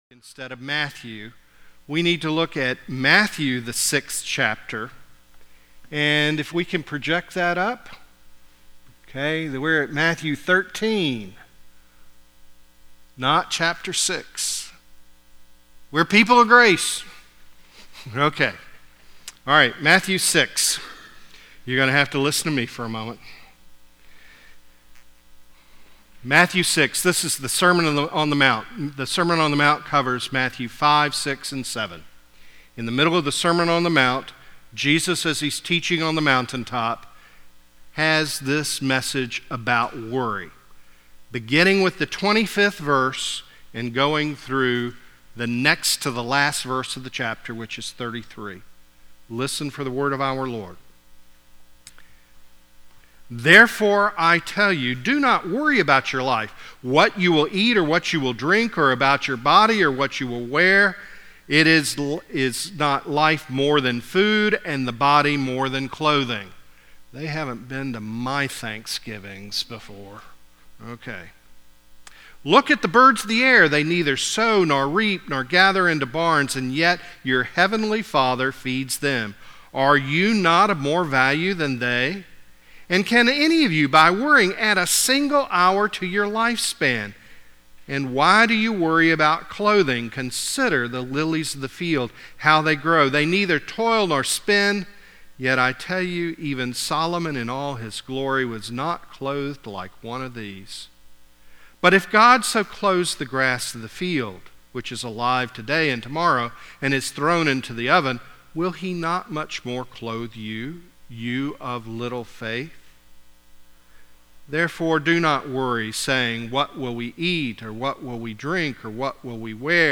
Tabernacle United Methodist Church Listen to Sermons